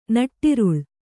♪ naṭṭiruḷ